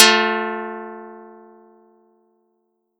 Audacity_pluck_9_13.wav